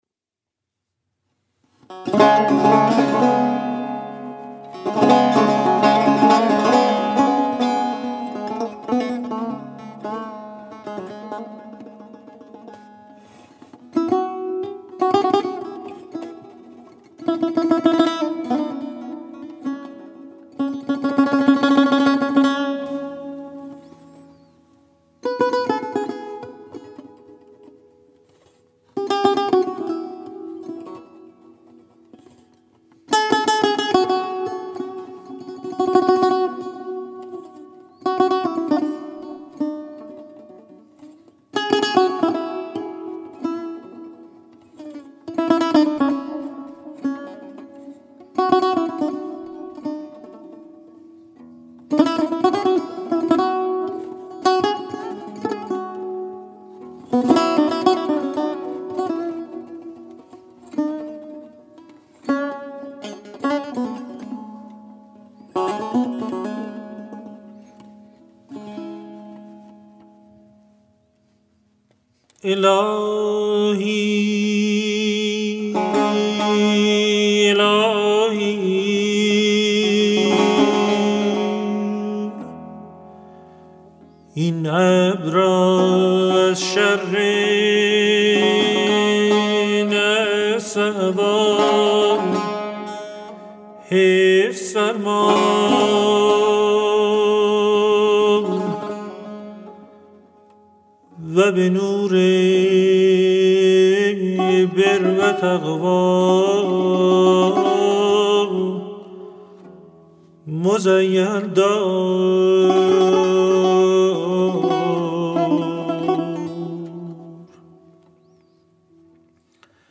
مجموعه مناجات های فارسی همراه با موسیقی
Monajat.mp3